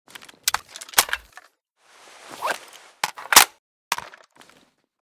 ppsh_reload.ogg.bak